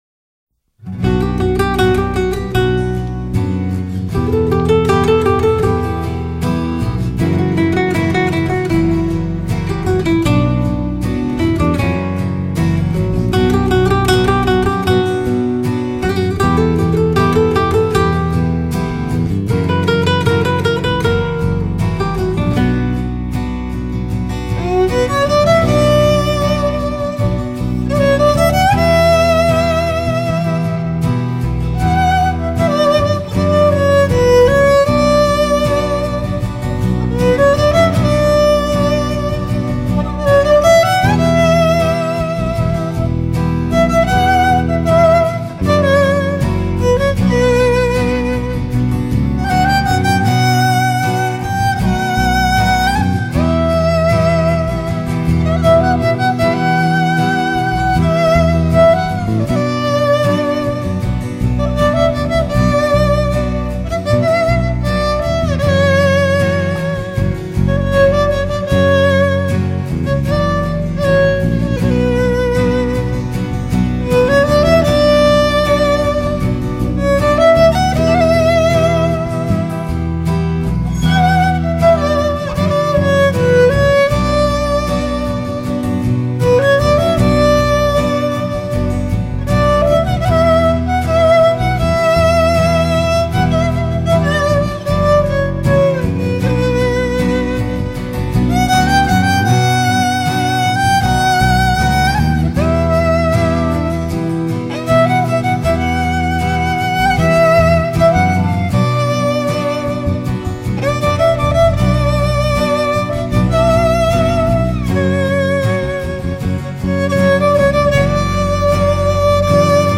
בסדרה האינסטרומנטלית